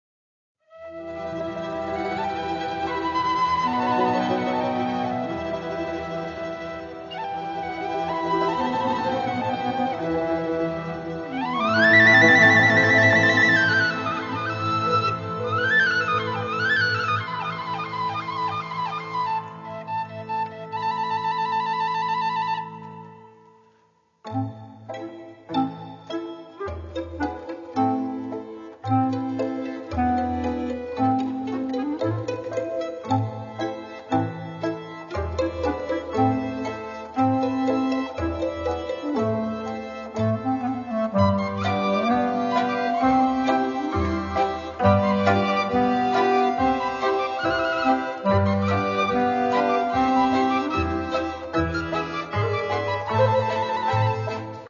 Каталог -> Народная -> Инструментальная